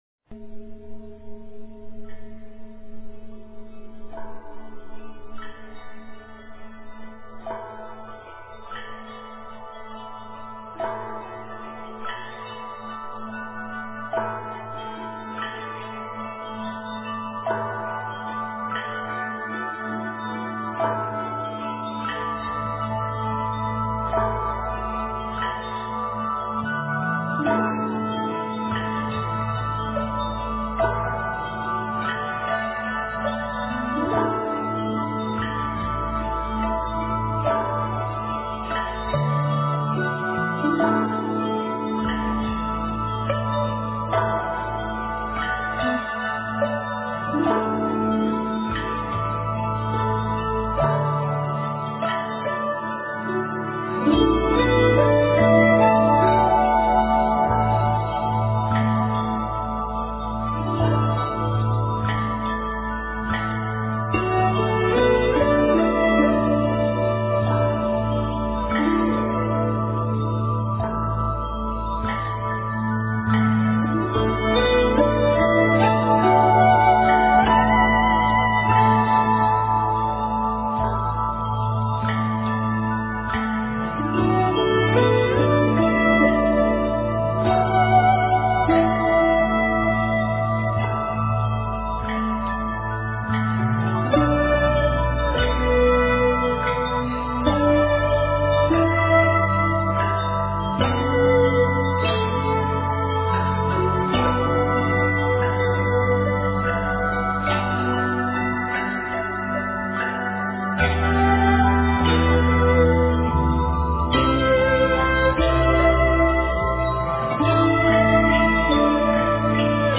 和谐 Harmony--禅定音乐